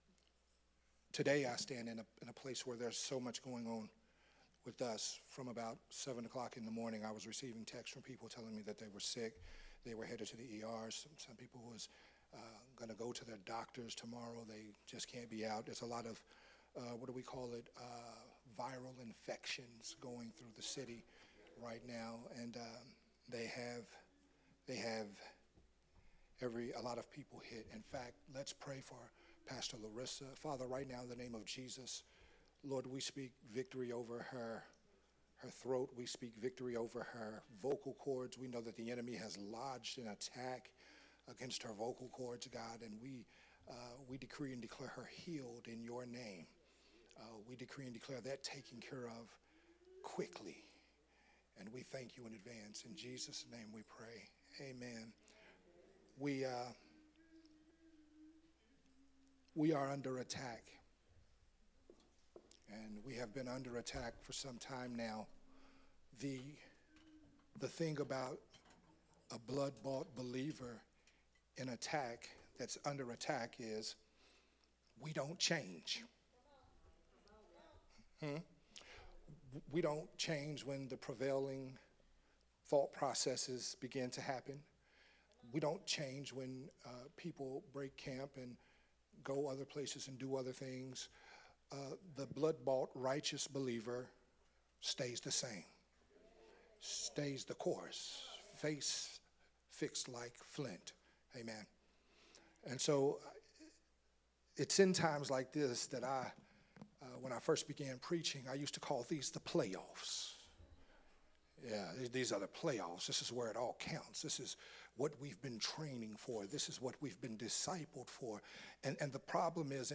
a Sunday Morning Worship Service message
recorded at Unity Worship Center on Sunday, November 17th, 2024.